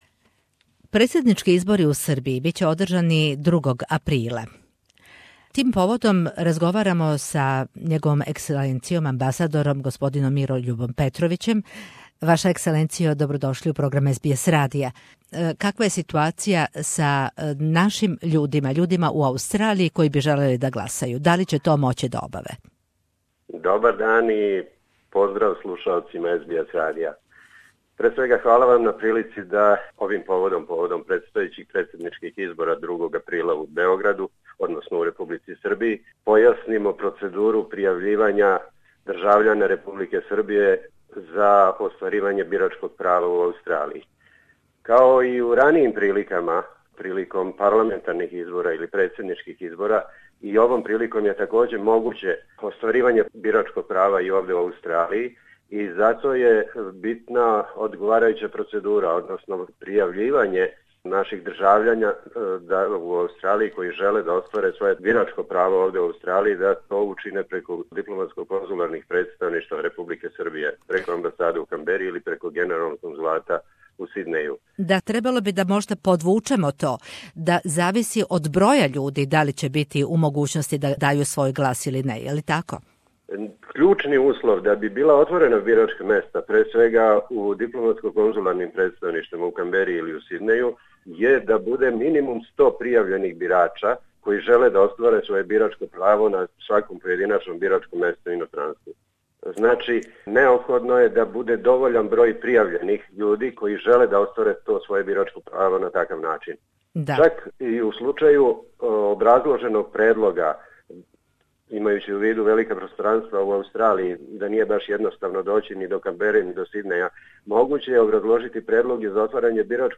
Председнички избори у Србији биће одржани 2. априла, а гласање ће бити организовано у Сиднеју и Канбери уколико се пријави најмање 100 српских држављана с правом гласа, каже за СБС радио Мирољуб Петровић, амбасадор Србије у Аустралији.